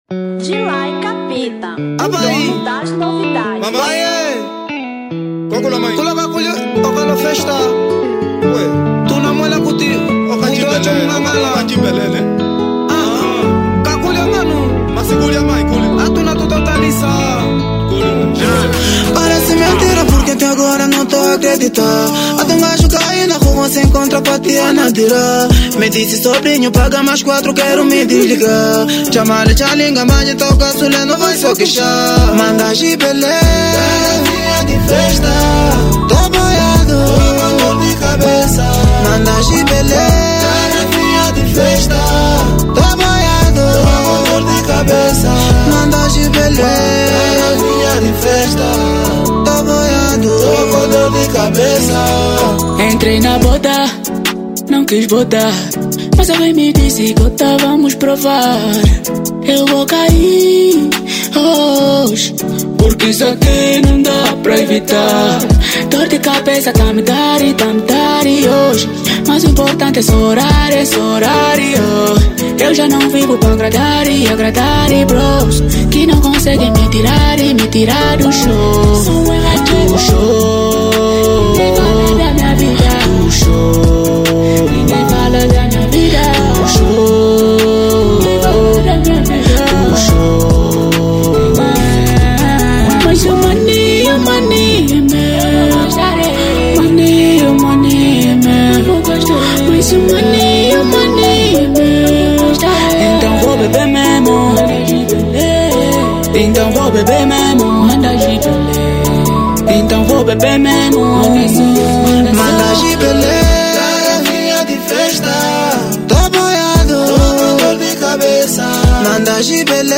Drill 2025